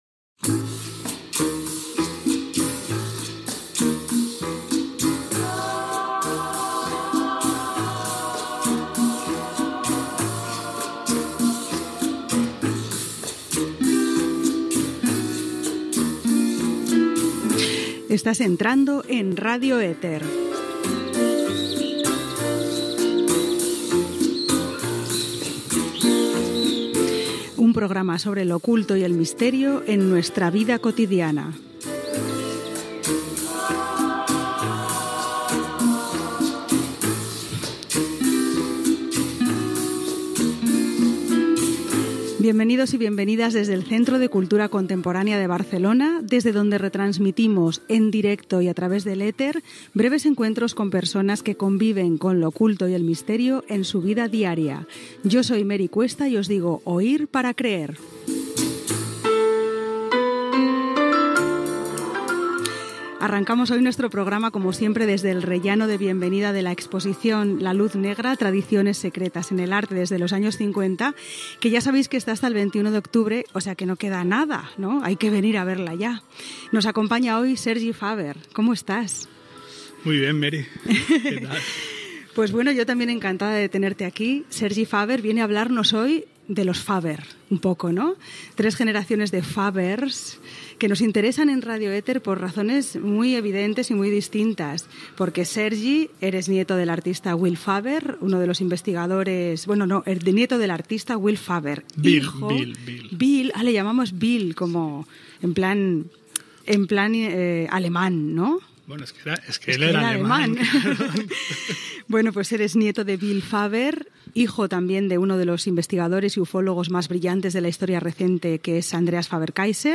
Cada programa es realitzava en directe des del set situat al replà de benvinguda de l’exposició «La llum negra».